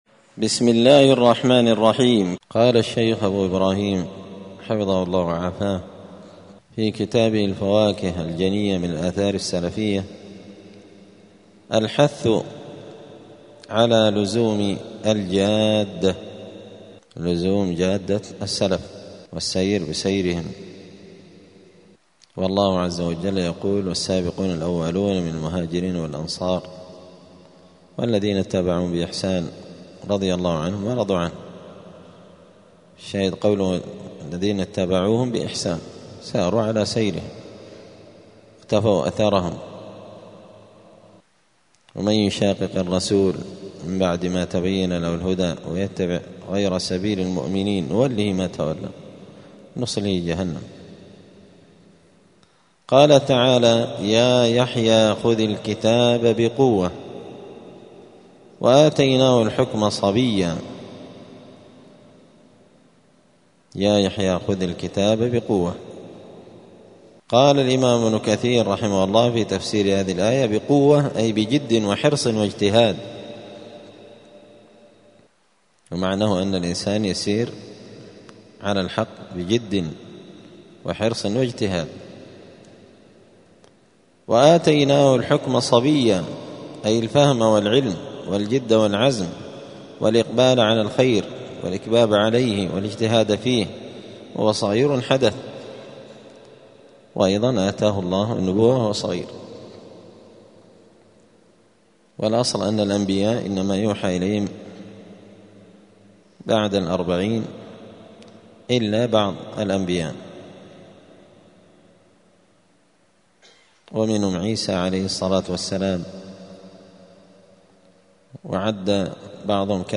دار الحديث السلفية بمسجد الفرقان بقشن المهرة اليمن
*الدرس السابع والستون (67) {باب الحث على لزوم الجادة}*